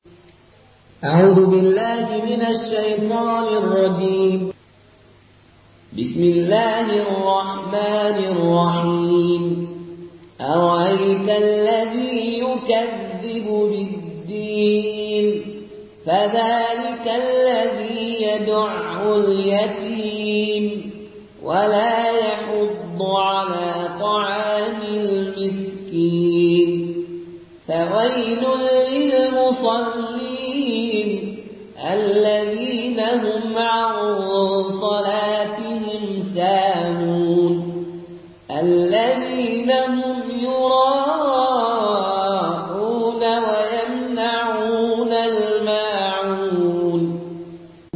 Une récitation touchante et belle des versets coraniques par la narration Qaloon An Nafi.
Murattal